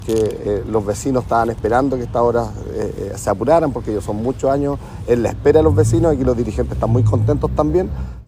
Héctor Muñoz, alcalde de Concepción, destacó la pronta apertura de una obra anhelada por los vecinos.
hector-munoz-paso-bajo-nivel.mp3